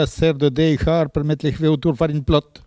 Catégorie Locution